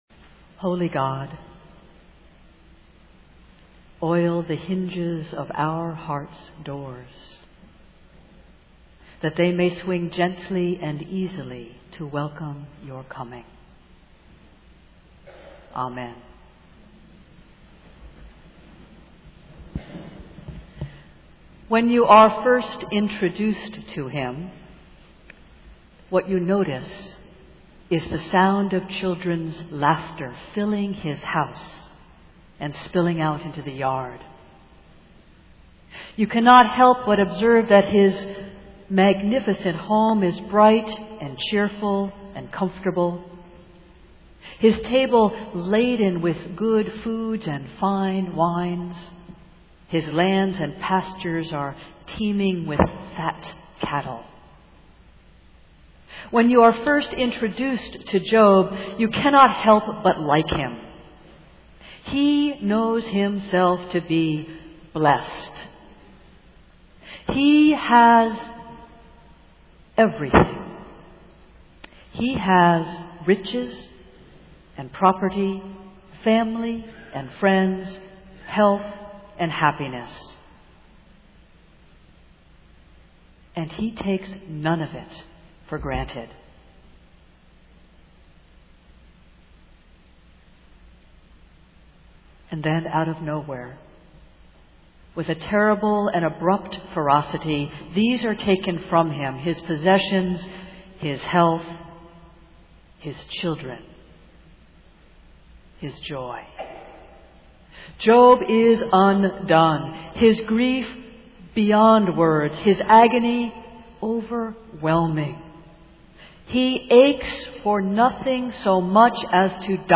Festival Worship